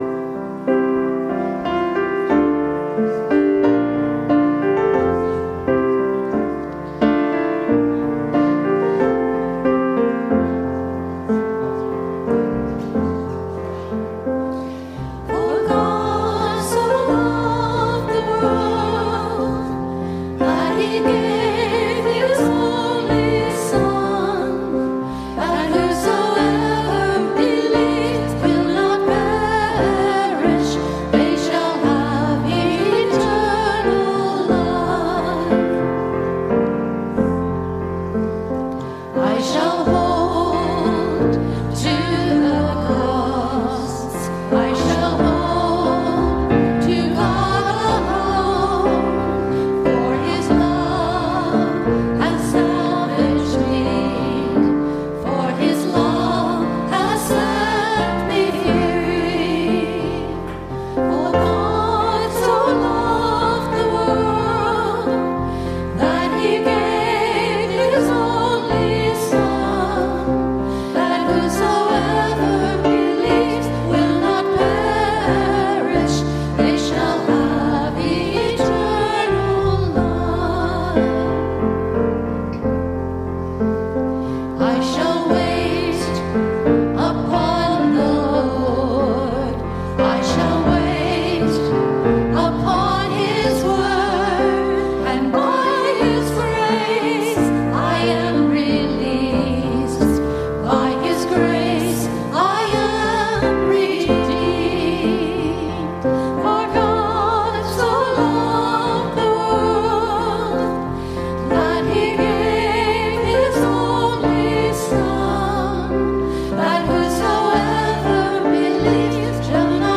Podcast from Christ Church Cathedral Fredericton
WORSHIP - 10:30 a.m. Sixth after Epiphany